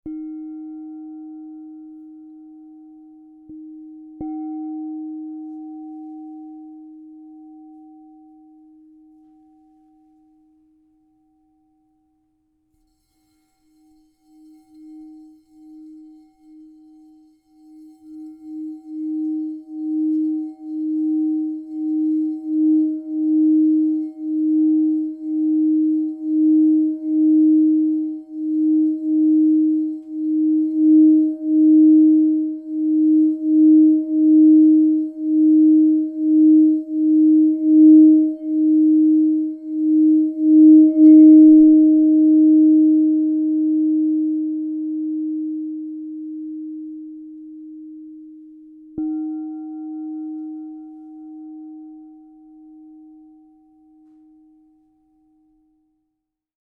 Morganite, Phenacite 8" D# 0 Crystal Tones singing bowl
The 8-inch size delivers focused and resonant tones, ideal for personal meditation, sound therapy, and enhancing sacred spaces.
Enhance your journey with 8″ Crystal Tones® True Tone alchemy singing bowl made with Morganite, Phenacite in the key of D# 0.
440Hz (TrueTone)